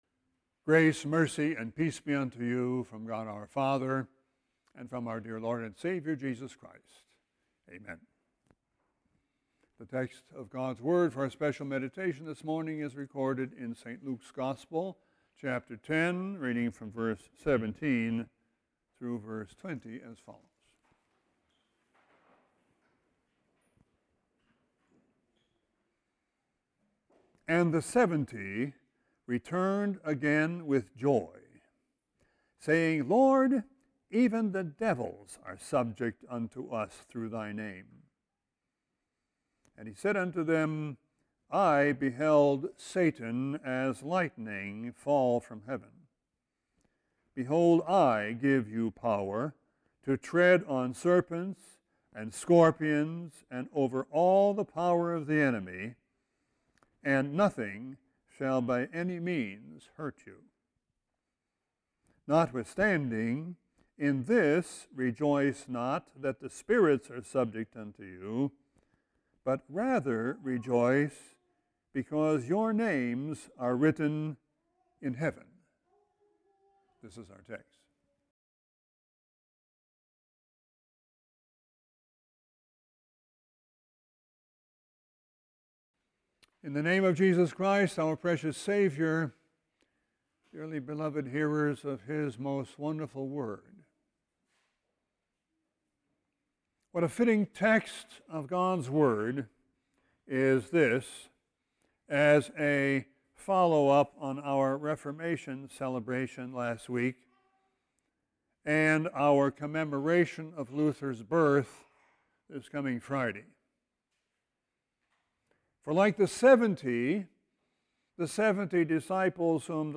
Sermon 11-5-17.mp3